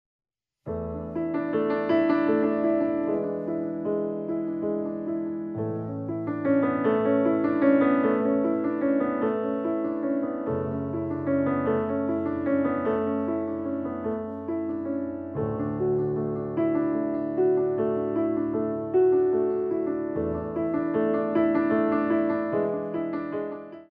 16x8 6/8